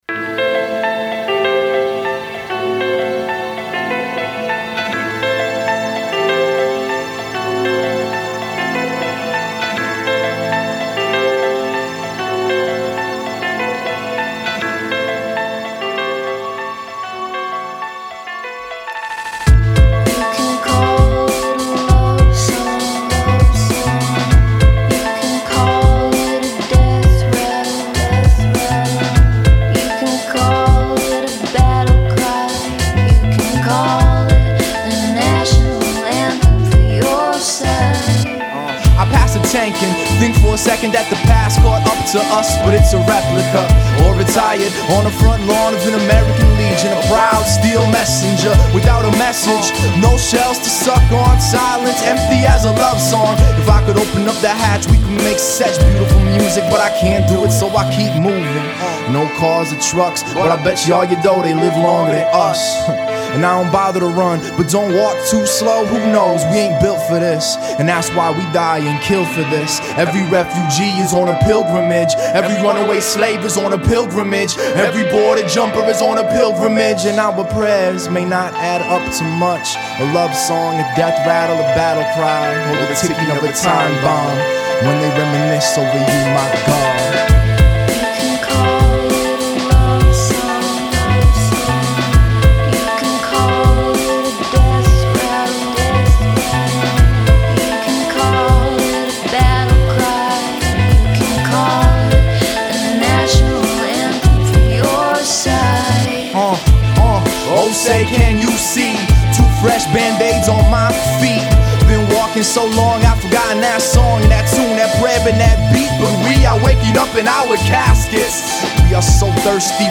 Twin City rapper/poet/activist
Hip Hop